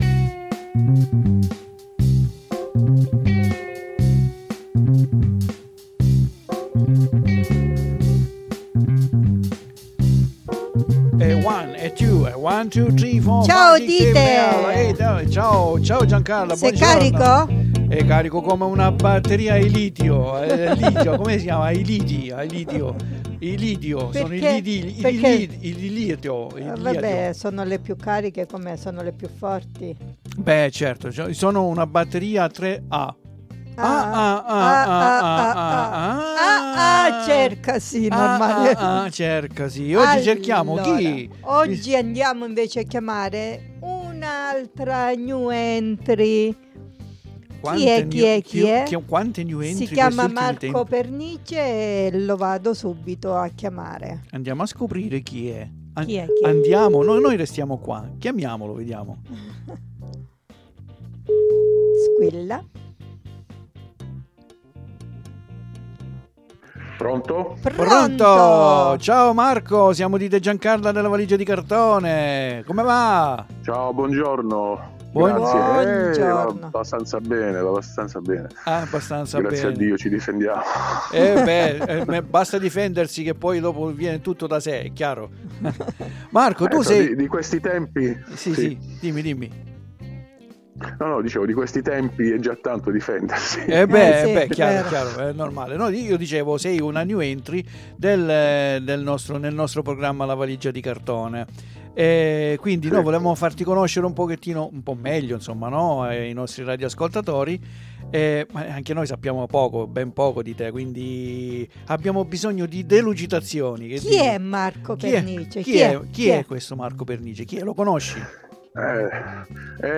SI RACCONTA AL TELEFONO!